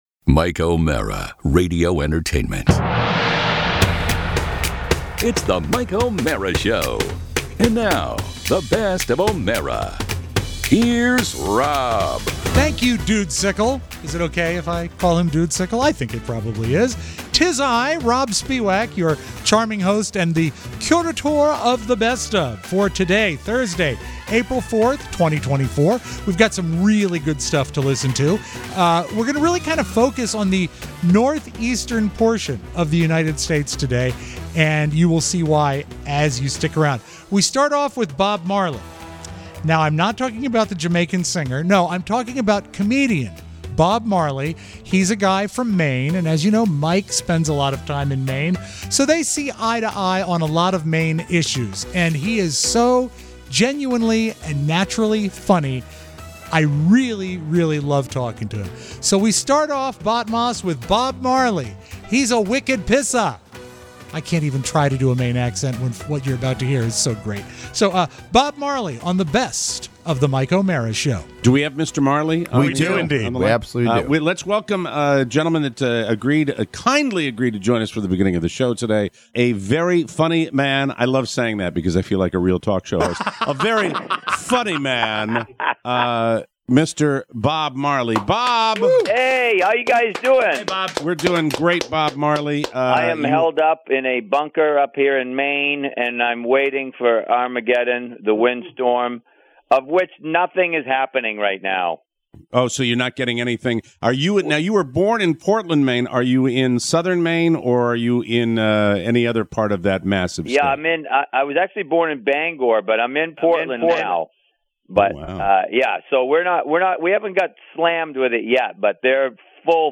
A phoner with funnyman Bob Marley...